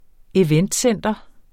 Udtale [ eˈvεnd- ]